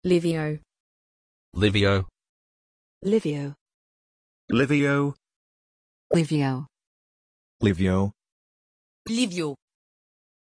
Aussprache von Lyvio
pronunciation-lyvio-en.mp3